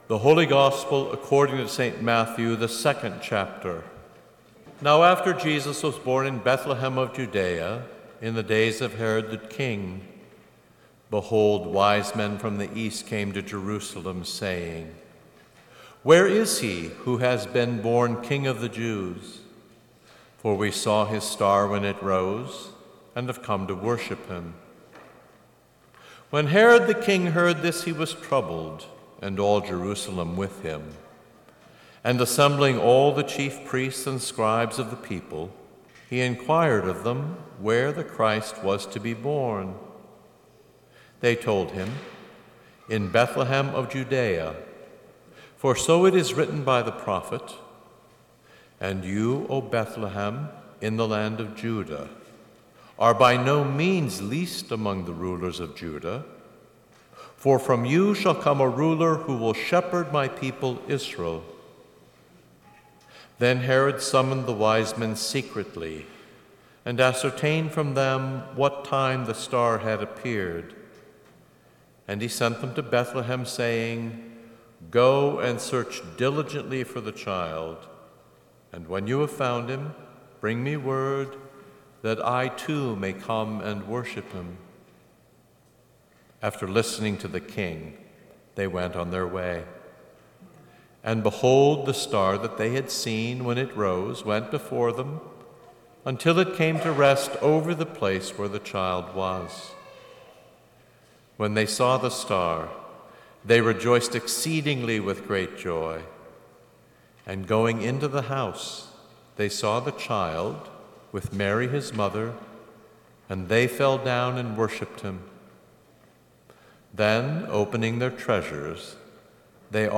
Listen to sermons from St. John Lutheran Church, Wheaton, IL